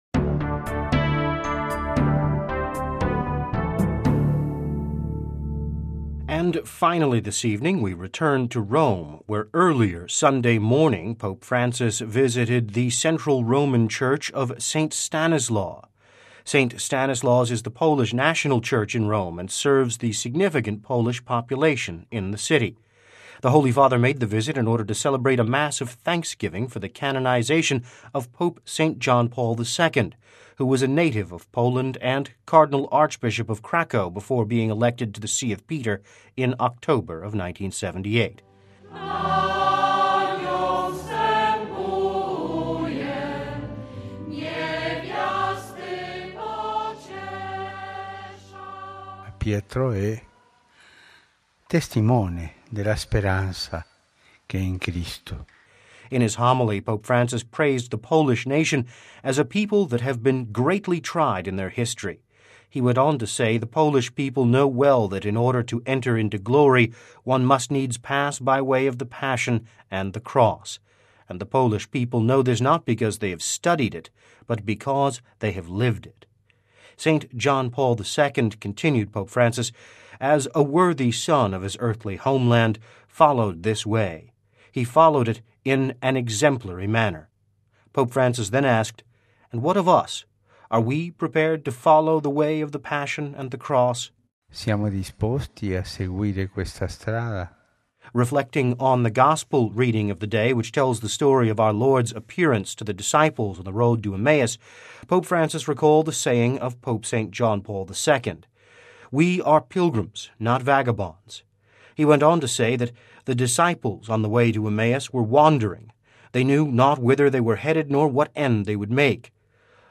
The Holy Father made the visit in order to celebrate a Mass of thanksgiving for the canonization of Pope St. John Paul II, who was a native of Poland and Cardinal Archbishop of Krakow before being elected to the See of Peter in October of 1978.